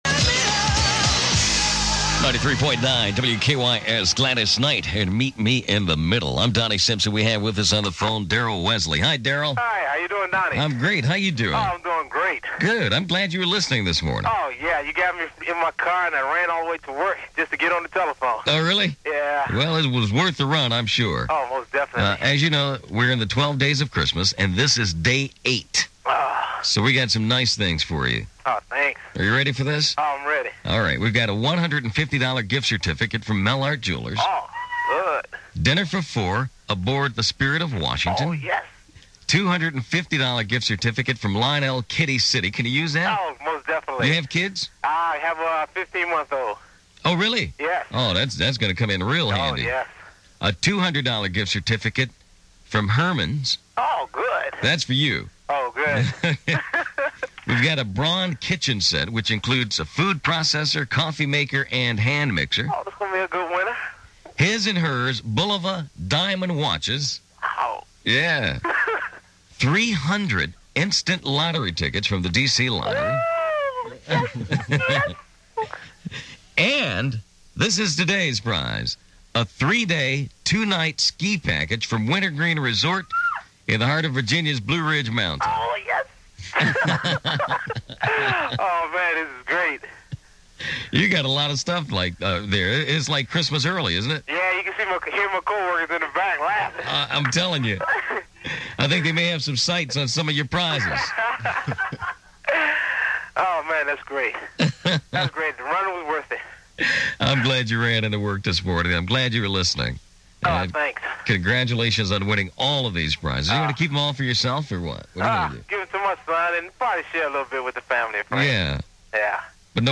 Donnie-Simpson-Aircheck.mp3